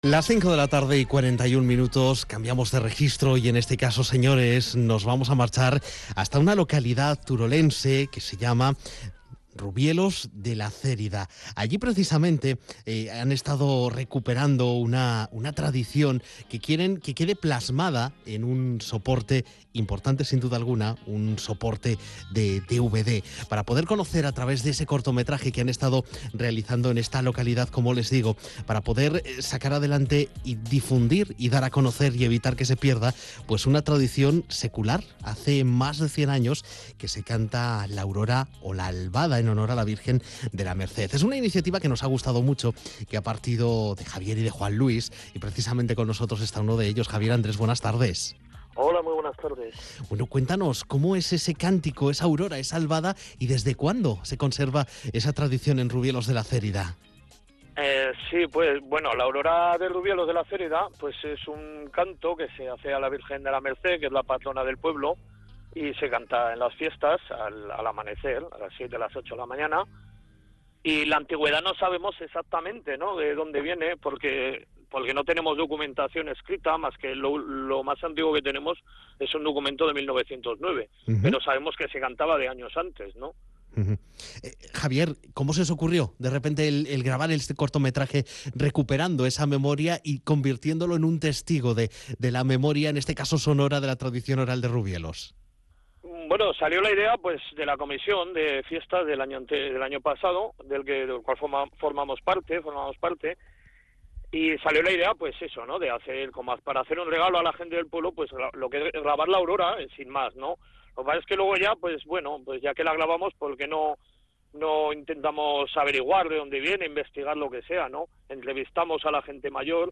Entrevista radiofónica sobre el documental de La Aurora 2007
entrevista aurora aragon radio.mp3